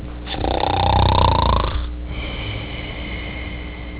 snore.wav